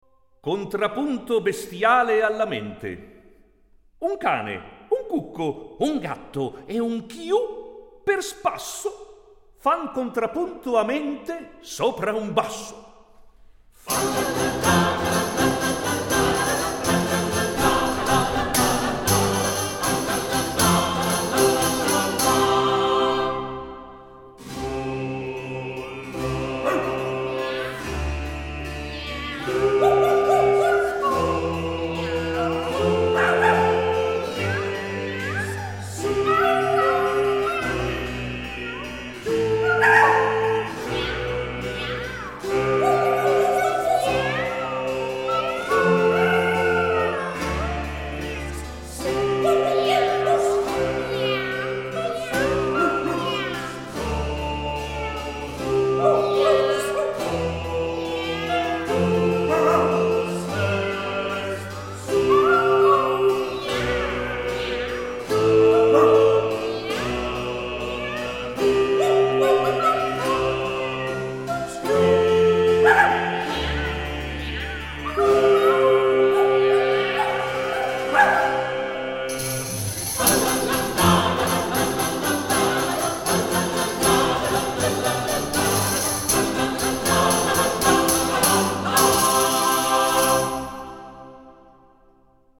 La seconda sezione, in tripla, che introduce il Contrappunto ha un passaggio che può essere efficacemente eseguito in emiolia, come propongono i 'Suonatori della gioisa marca' diretti da Diego Fasolis (Naxos 1997).
«Alla mente» significa improvvisato, a sottolinearne la semplicità.